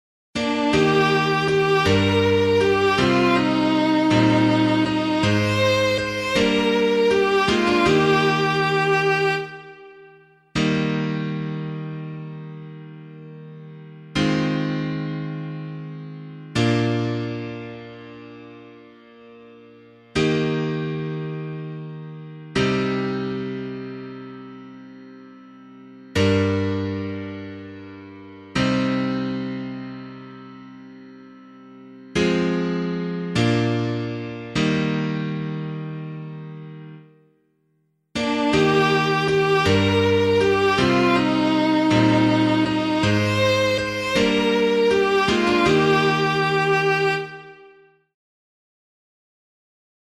E♭ major